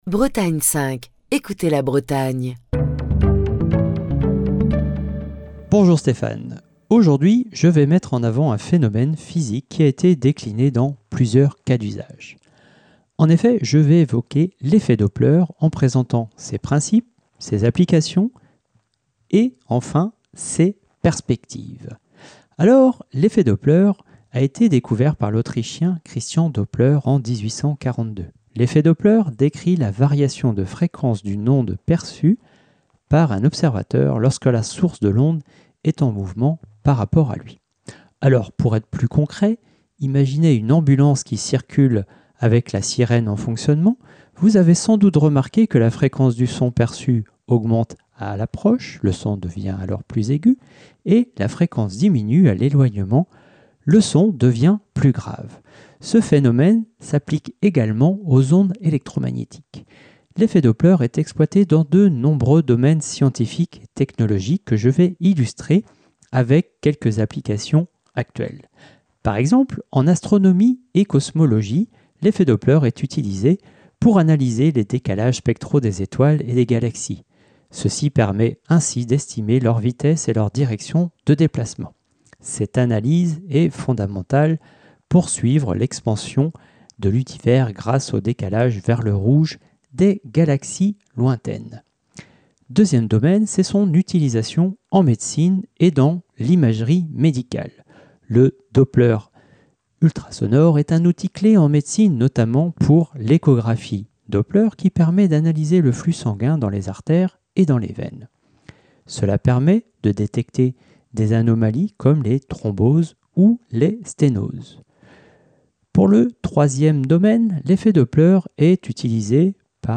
Chronique du 26 mars 2025.